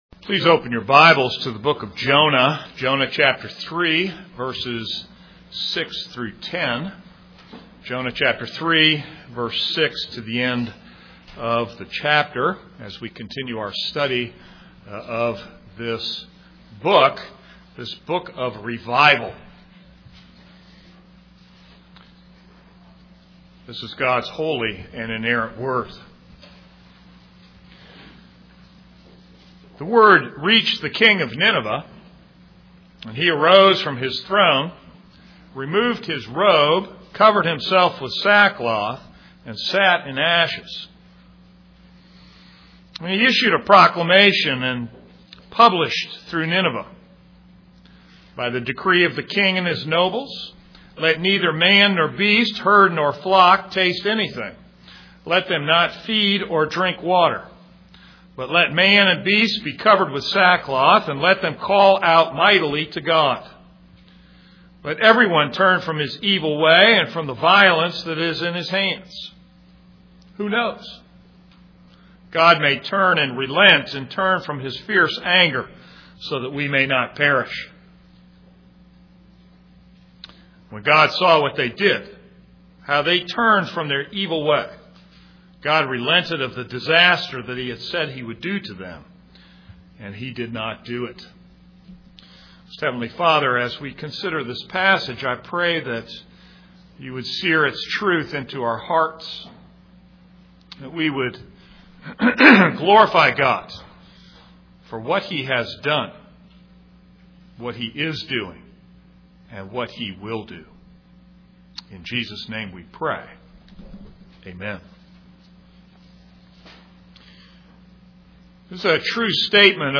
This is a sermon on Jonah 3:6-10.